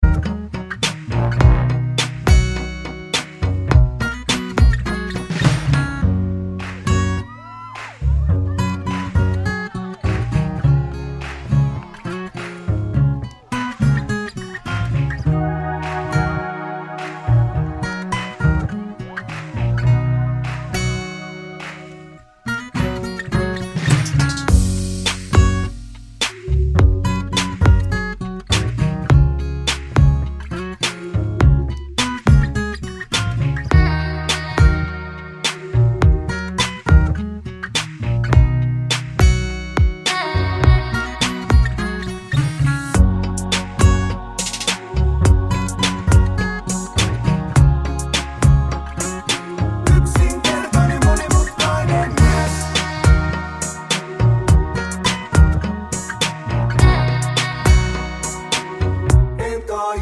no Backing Vocals Finnish 3:28 Buy £1.50